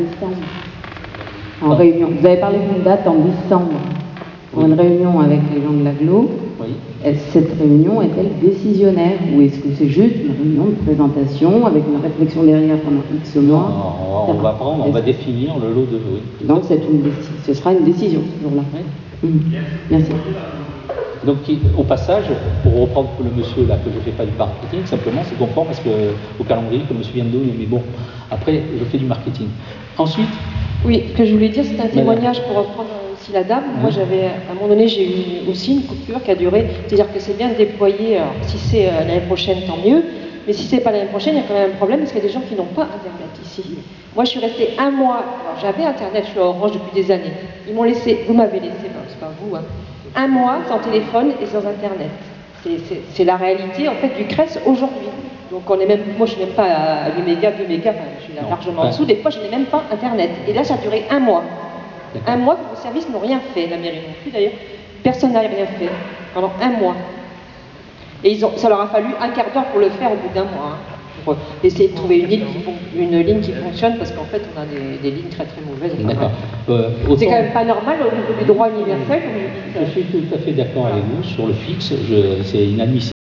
C.R. REUNION PUBLIQUE INTERNET HAUT DEBIT AU CRES : ORANGE demande aux créssois d'attendre des jours meilleurs .... des années plus exactement !
De nombreux créssois ont alors pris la parole avec parfois une rage à peine contenue tant la situation de l'Internet au Crès est désespérante.